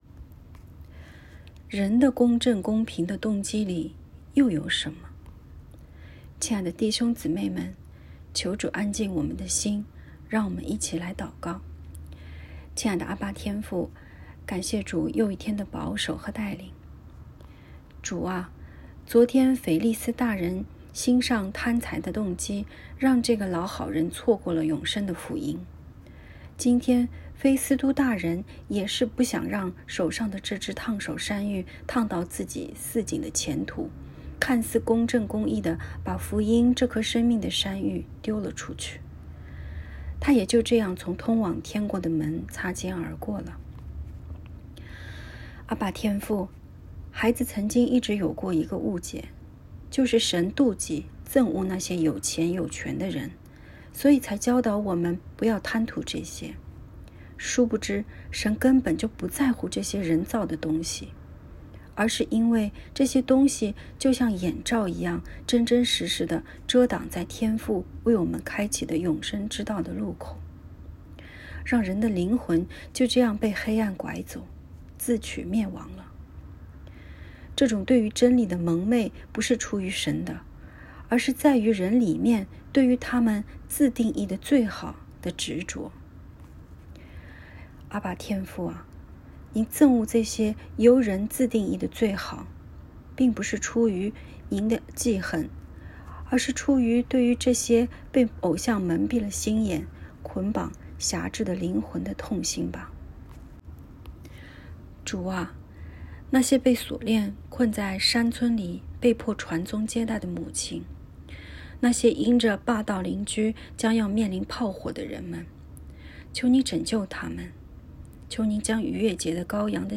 ✨晚祷时间✨2月24日（周四）